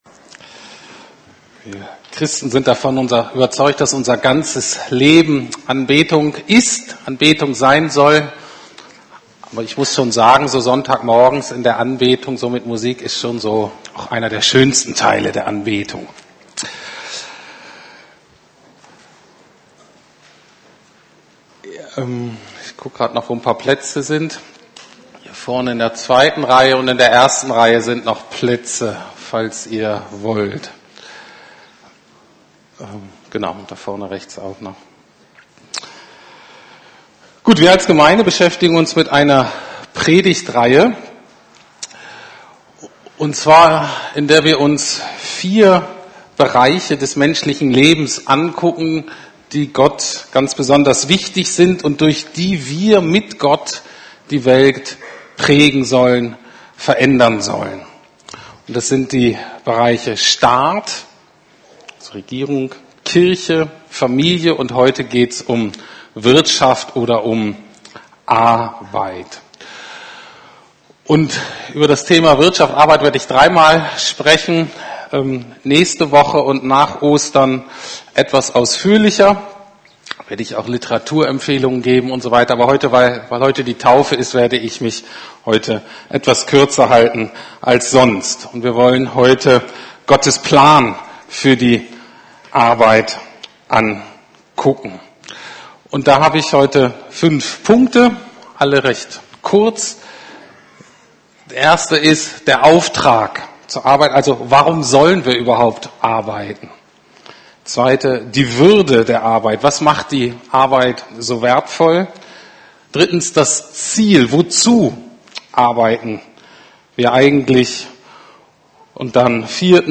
Wie Gott die Welt regiert - WIRTSCHAFT; Teil 1: Gottes Plan für die Arbeit ~ Predigten der LUKAS GEMEINDE Podcast